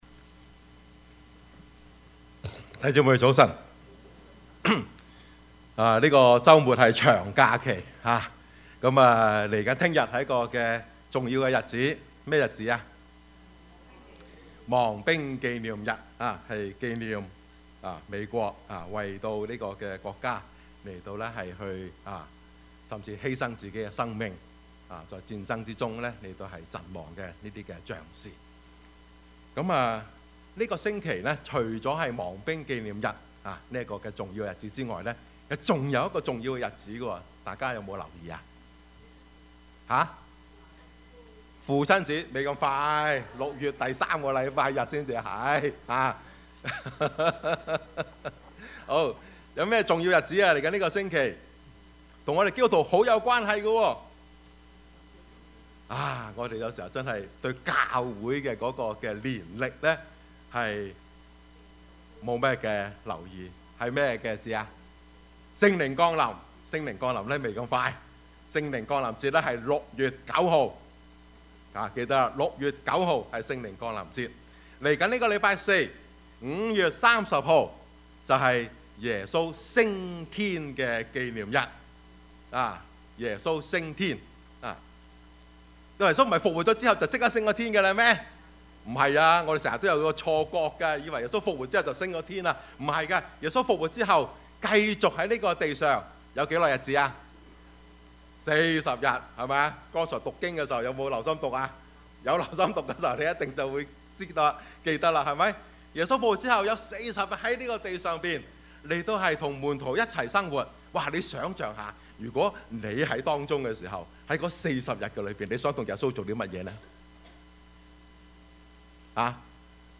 Service Type: 主日崇拜
Topics: 主日證道 « 團契與關懷 得知叛逆需回轉 »